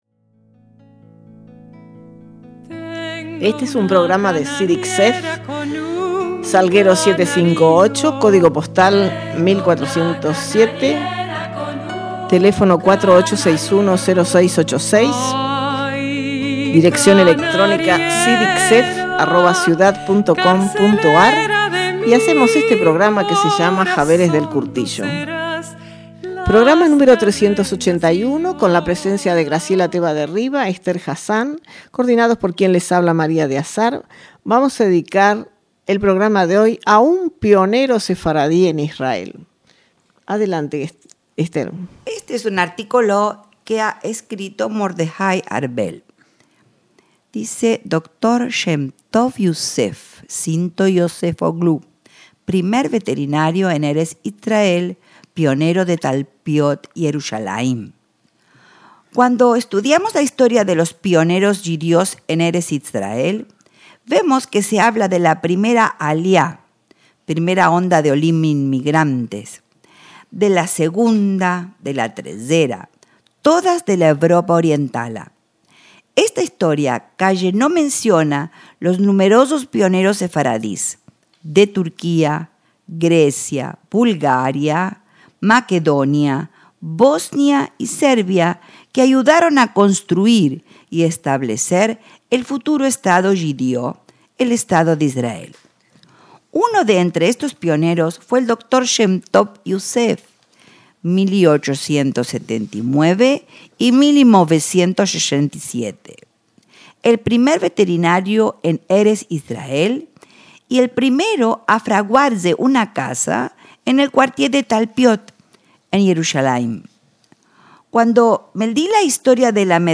JAVERES DEL KURTIJO – En su emisión Nº 381, las compañeras del CIDICSEF nos deleitan con un relato sobre el primer veterinario de Israel, Shemtov Yosef, que fue también el primero en construirse una casa en el barrio de Talpiot, en Jerusalén.